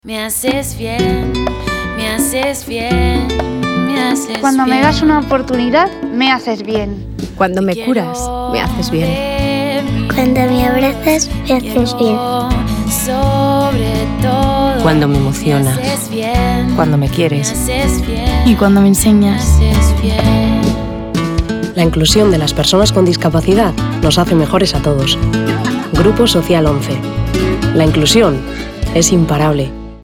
Tanto en los spots de televisión como en las cuñas de radio escuchamos los testimonios de distintas personas con y sin discapacidad, en los que van haciendo distintas afirmaciones (“cuando me das una oportunidad...”, “cuando me curas”, “cuando me escuchas”, “cuando me abrazas”, “cuando me emocionas”, “cuando me enseñas”, “cuando me impulsas”, “cuando me quieres y me haces reír”,....), todas ellas replicadas con la misma declaración: “Me haces bien”.
SPOTS RADIO
Voz femenina